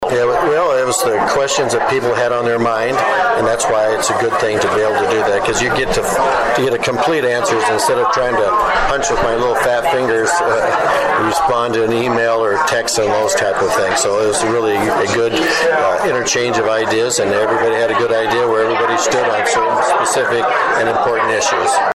District 18 Legislative Cracker Barrel Held in Yankton